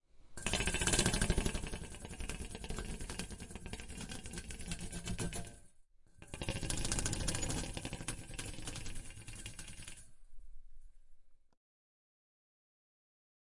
描述：手指和百叶窗